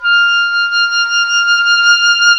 WND OBOE2 0H.wav